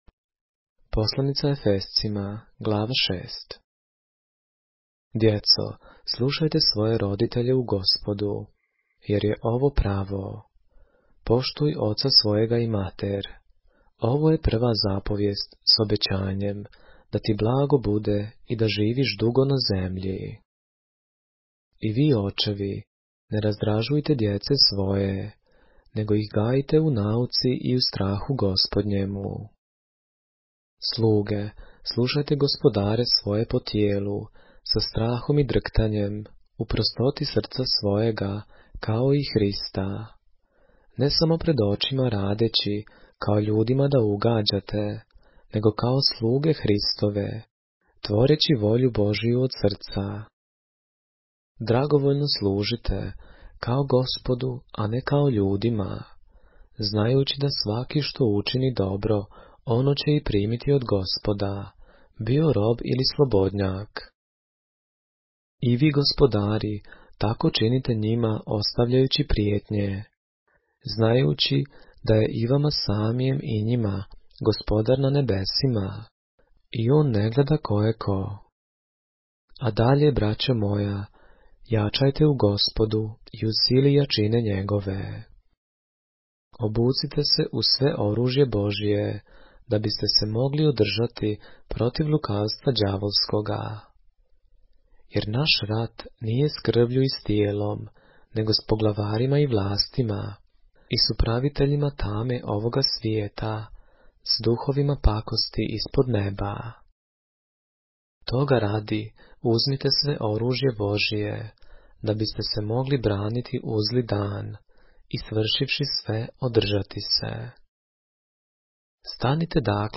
поглавље српске Библије - са аудио нарације - Ephesians, chapter 6 of the Holy Bible in the Serbian language